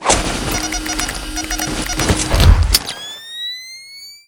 battlesuit_wear.wav